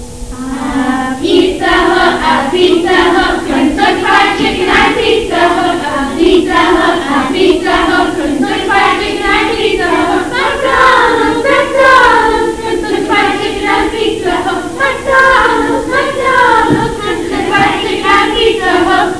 Click on any of the following songs to hear us singing: